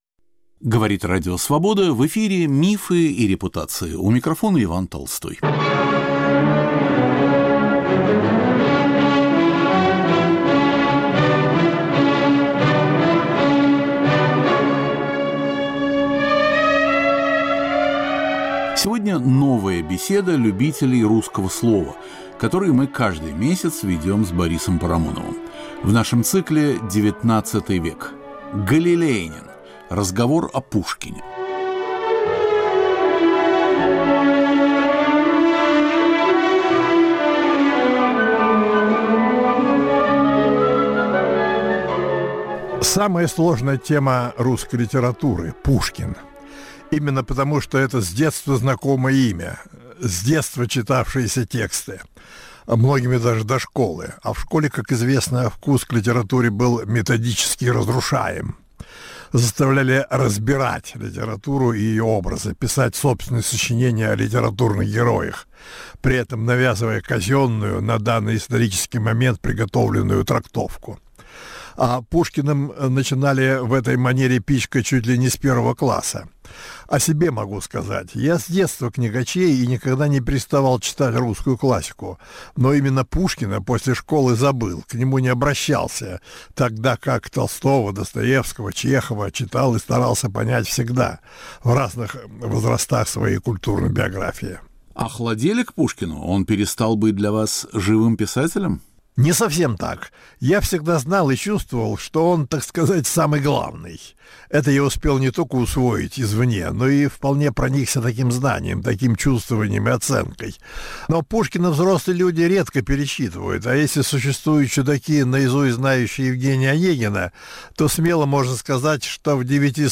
Галилеянин. Новая беседа любителей русского слова. Борис Парамонов и Иван Толстой говорят о Пушкине.